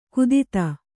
♪ kudita